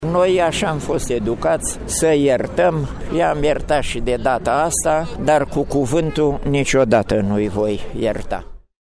Foștii deținuți politic au fost comemorați, la Brașov, la monumentul din Piața Teatrului Sică Alexandrescu.